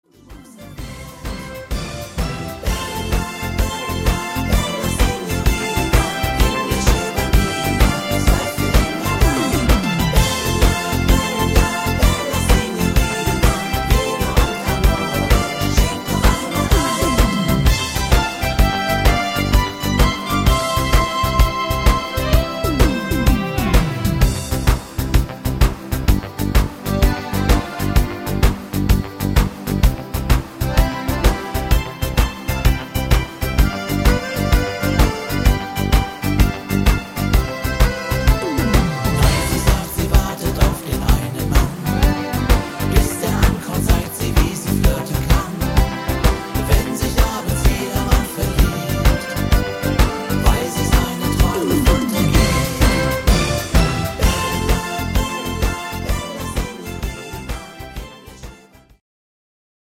Rhythmus  Tarantella
Art  Deutsch, Schlager 2010er